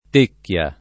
tecchia [ t % kk L a ]